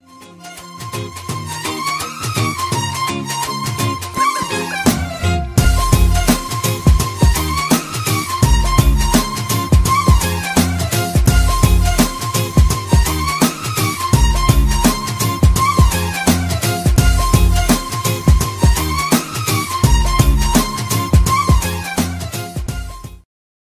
Punjabi Ringtones